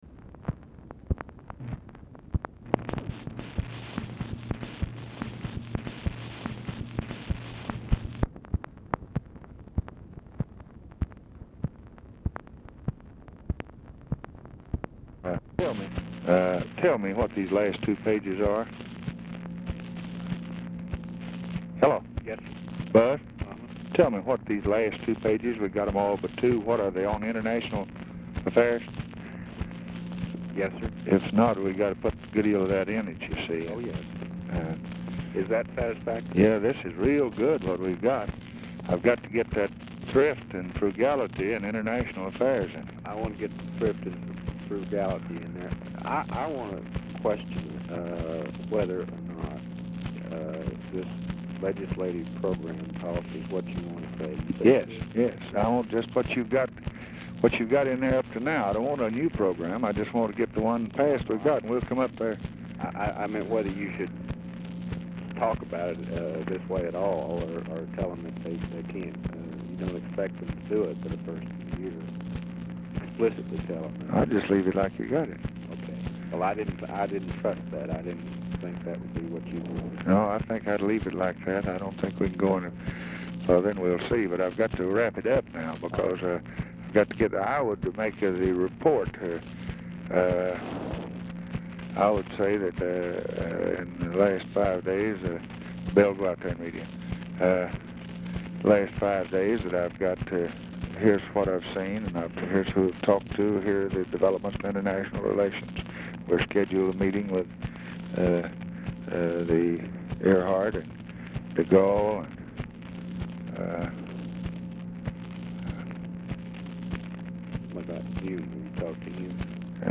Format Dictation belt
Specific Item Type Telephone conversation